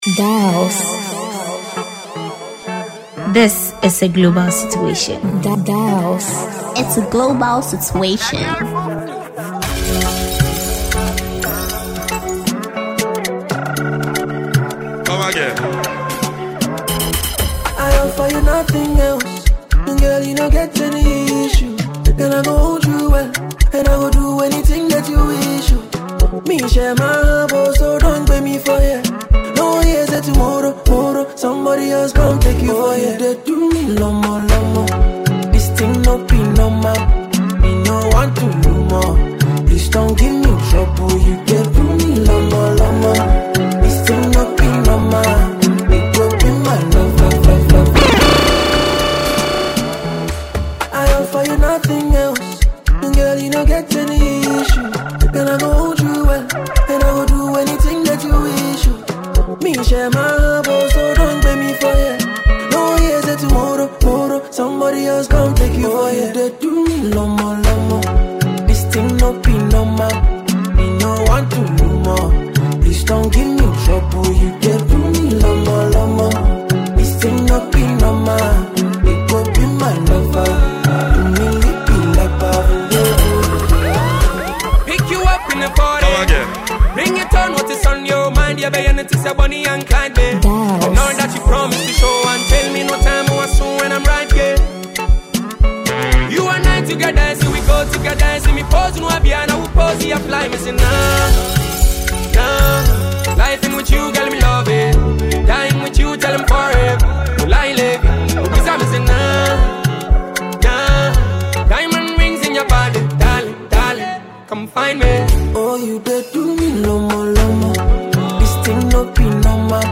a versatile Ghanaian Disc Jockey
Ghana Afrobeat MP3